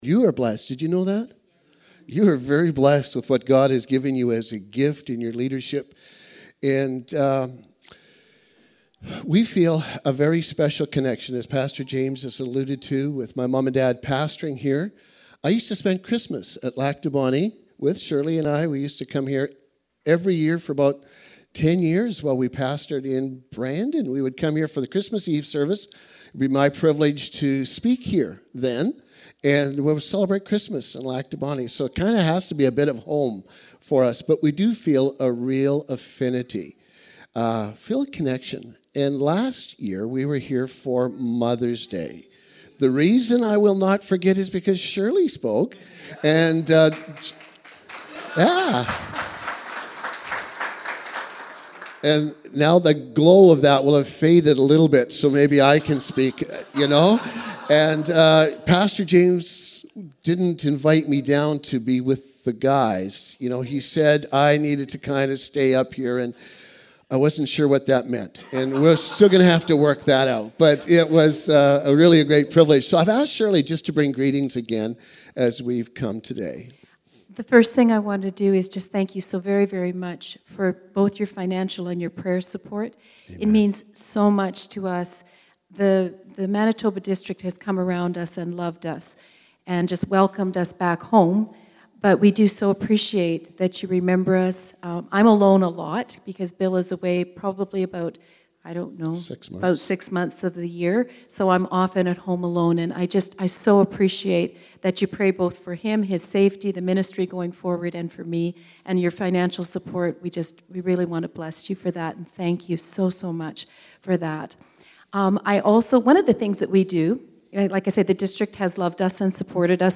Sermons | Abundant Life Chapel
Guest Speaker